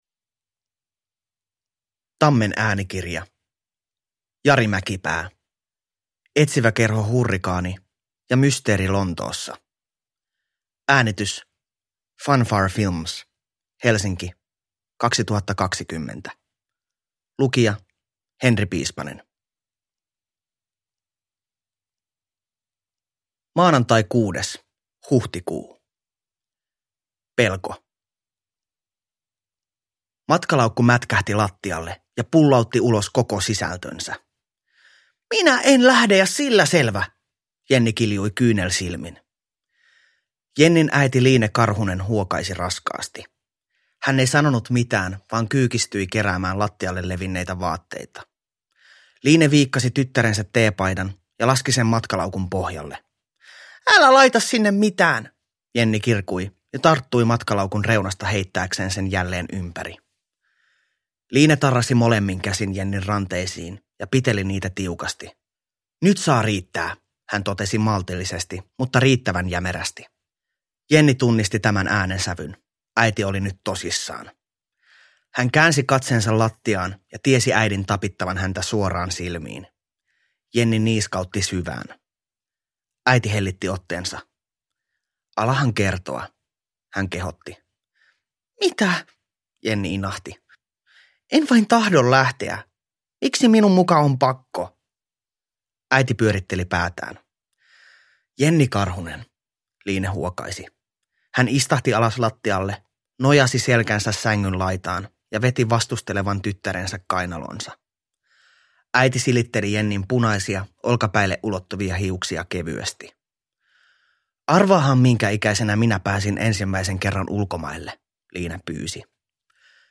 Etsiväkerho Hurrikaani ja mysteeri Lontoossa – Ljudbok – Laddas ner